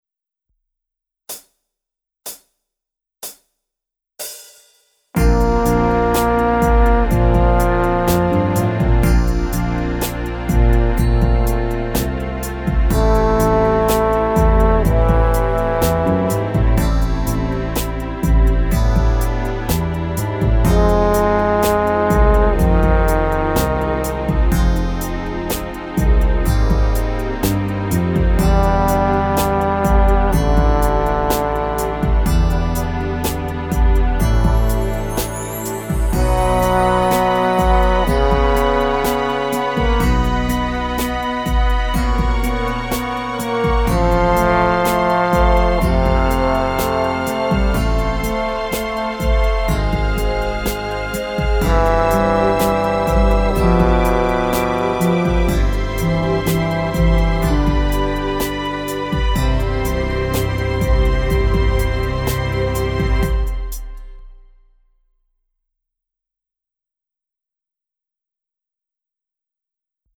Trombone Solo